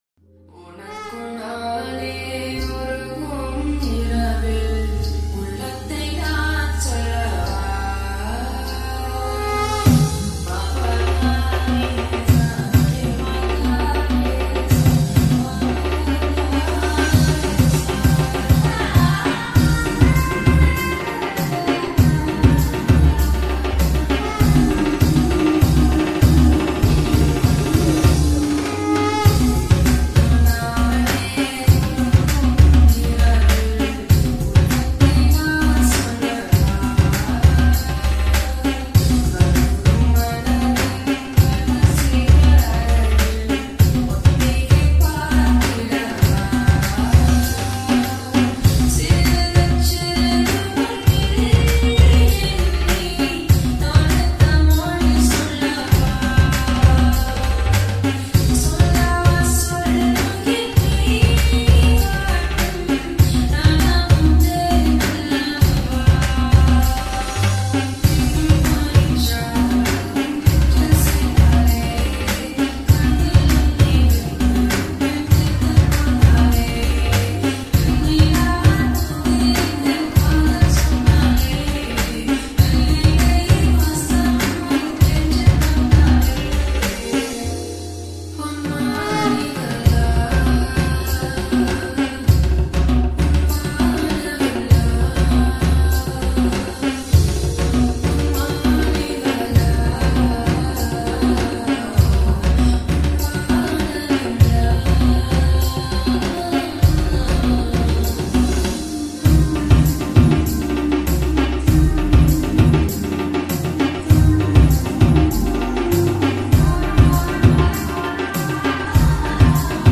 Sri Lankan Traditional Remix